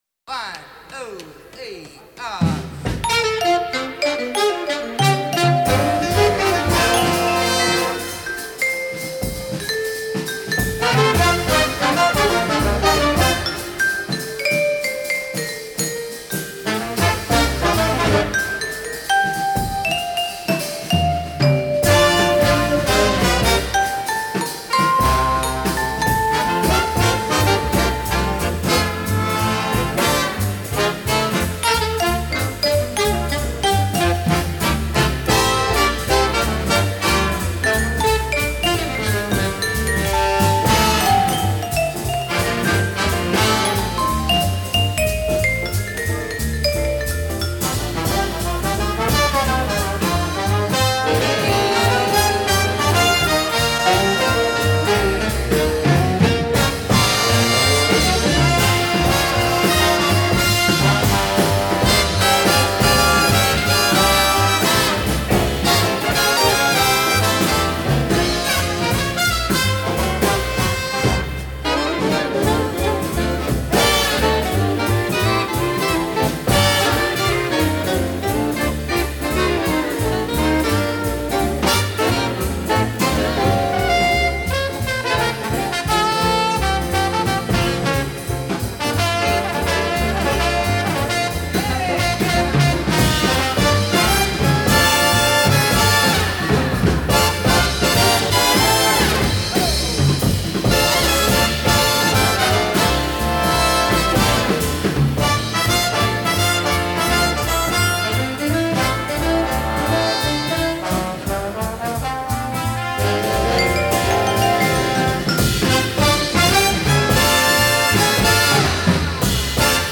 Trumpets
Trombones
Saxophones
Piano
Bass
Drums
Vibes and Leader